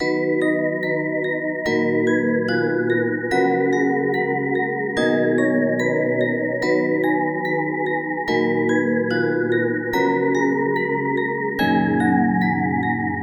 这些铃铛的风格是Unotheactivist x Smokepurpp。
标签： 145 bpm Trap Loops Bells Loops 2.23 MB wav Key : C
声道立体声